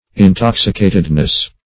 Search Result for " intoxicatedness" : The Collaborative International Dictionary of English v.0.48: Intoxicatedness \In*tox"i*ca`ted*ness\, n. The state of being intoxicated; intoxication; drunkenness.